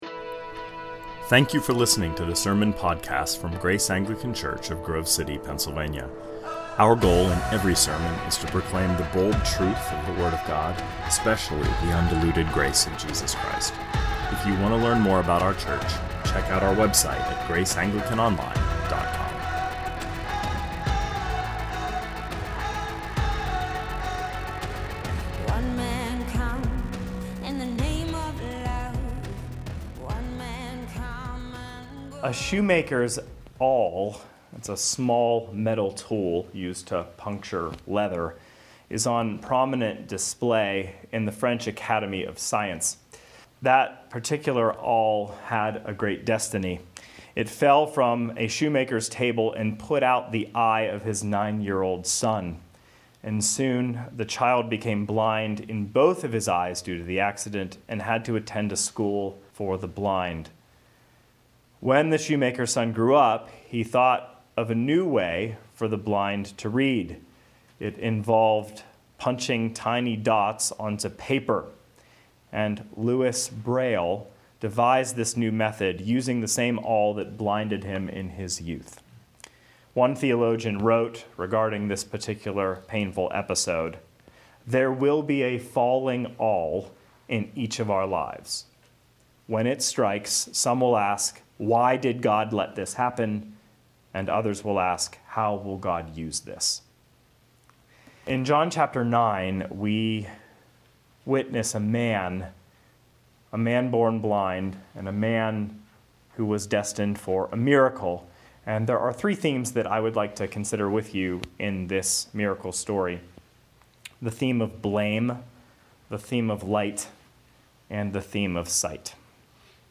2020 Sermons